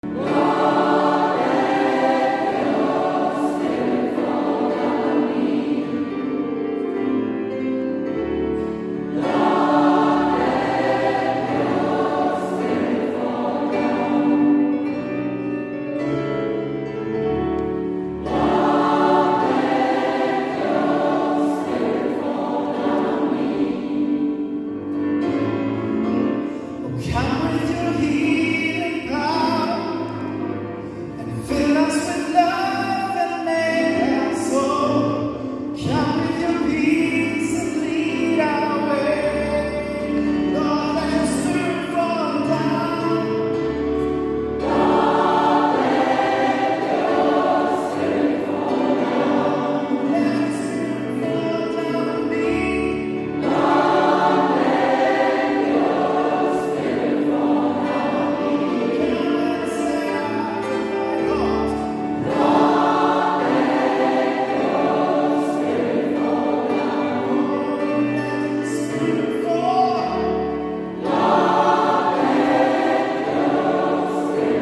(Chorsatz).